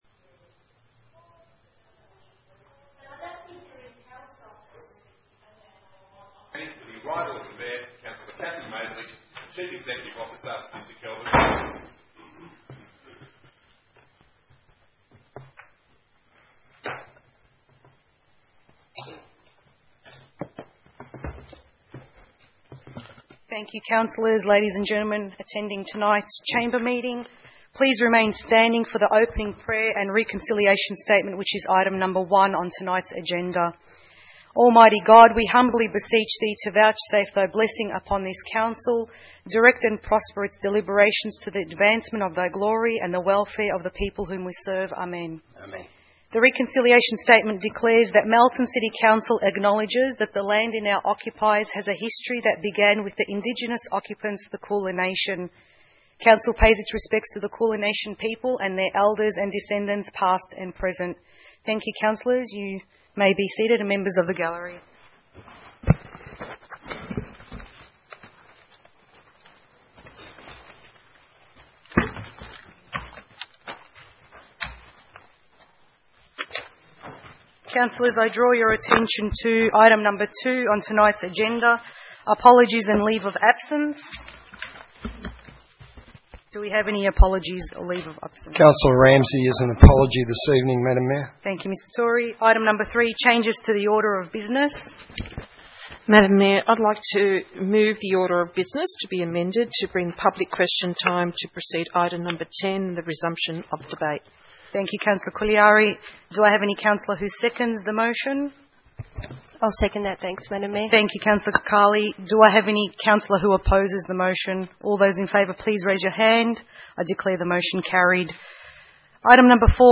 27 June 2016 - Ordinary Council Meeting